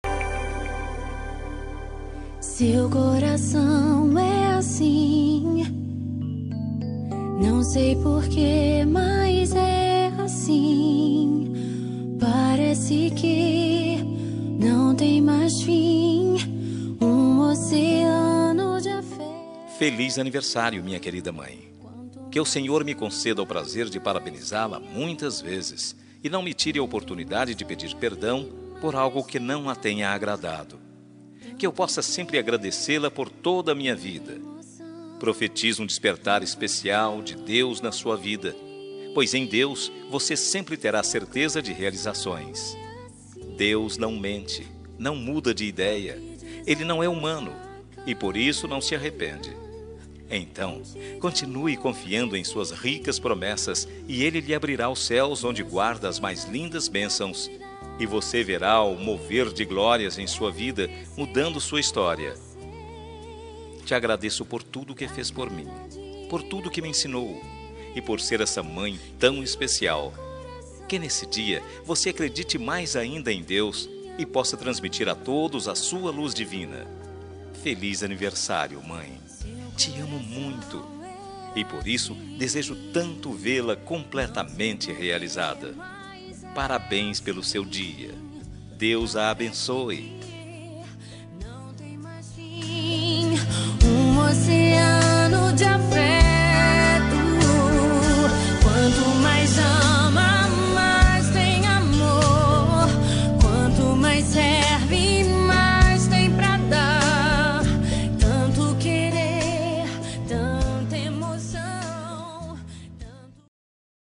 Aniversário de Mãe Gospel – Voz Masculina – Cód: 6068